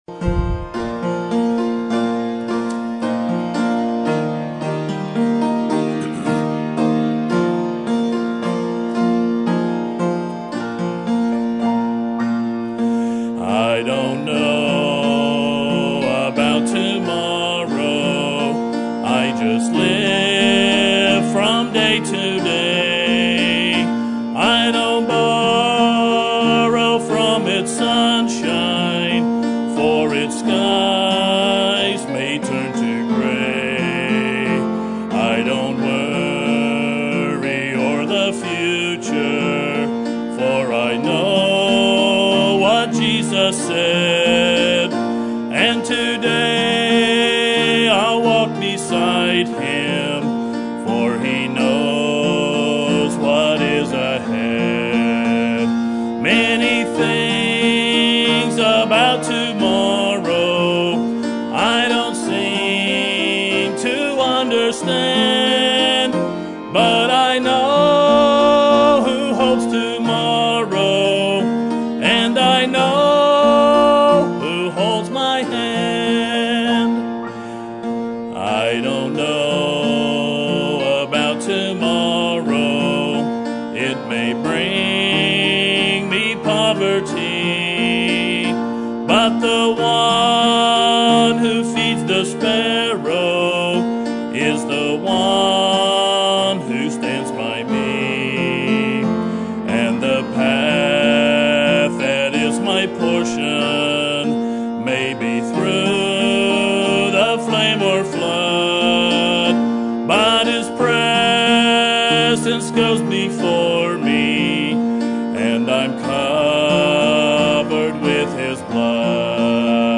Sermon Topic: General Sermon Type: Service Sermon Audio: Sermon download: Download (20.92 MB) Sermon Tags: 2 Chronicles Pride Destructive Sin